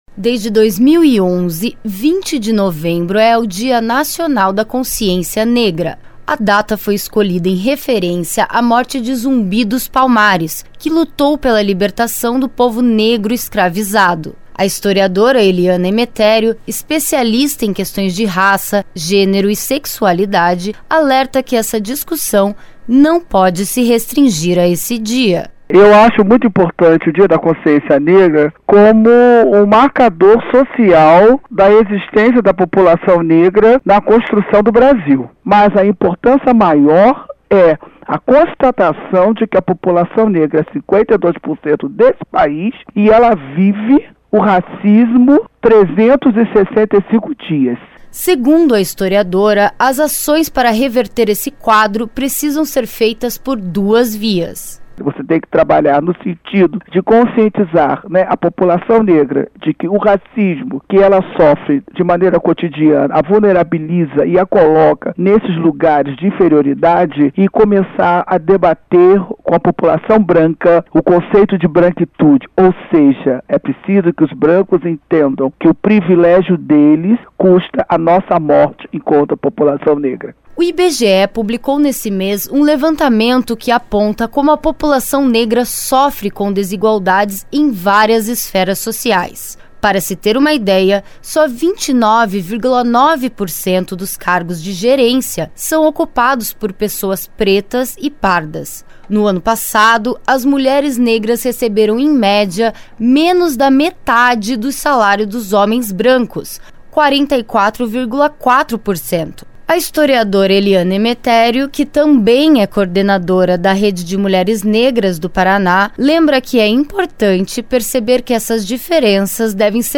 Em outros países o período começa em 25 de novembro, mas no Brasil as ações começam nesta quarta, como explica a deputada.